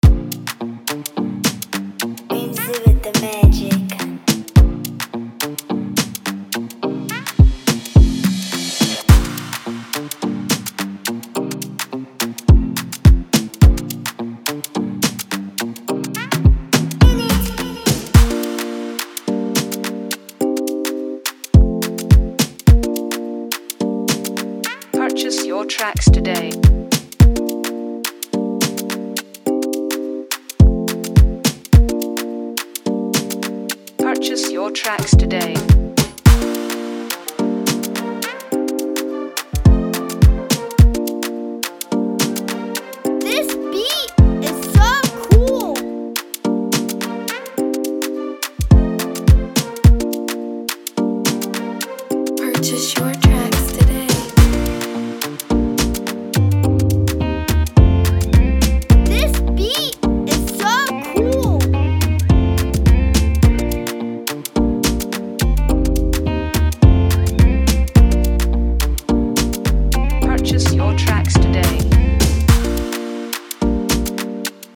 type beat instrumental